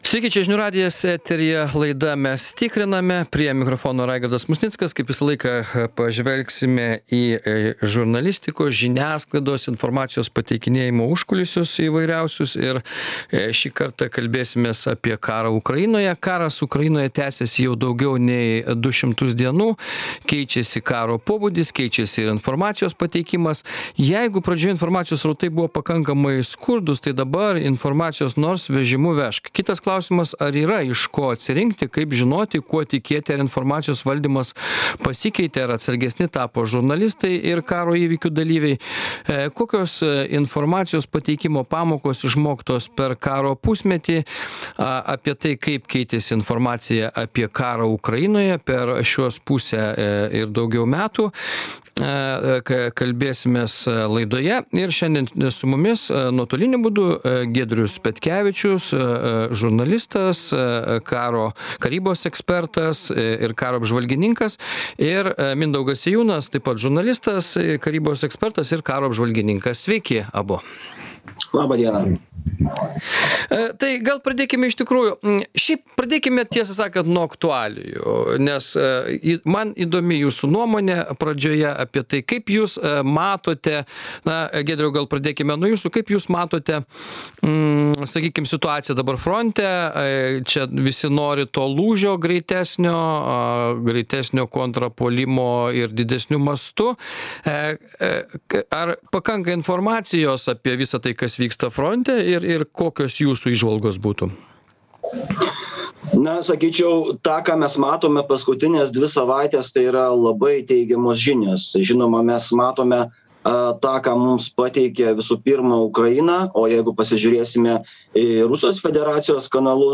Diskutuoja: žurnalistas, karo apžvalgininkas